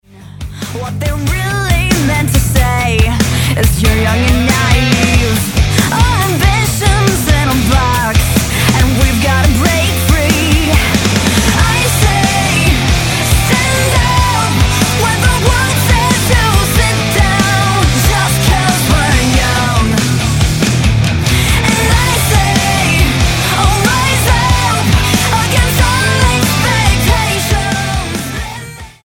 Rock Album
Style: Pop